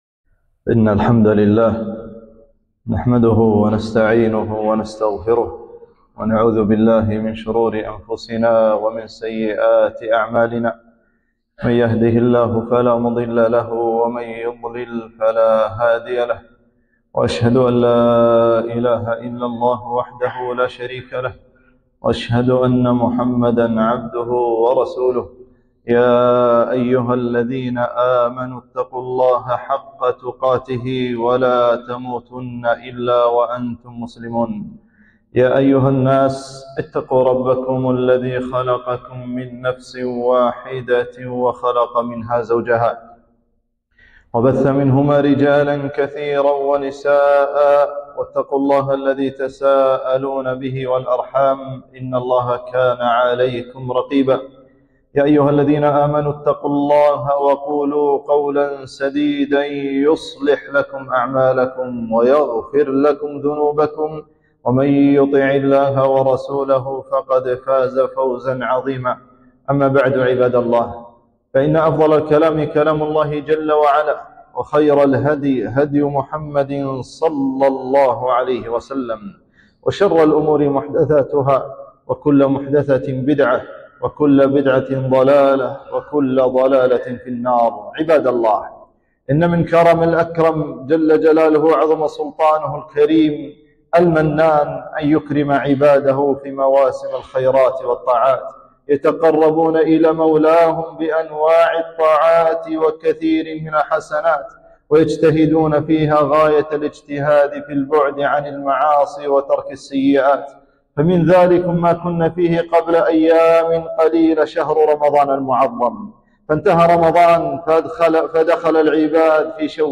خطبة - أفضل أيام الدنيا عشر ذي الحجة